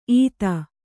♪ īta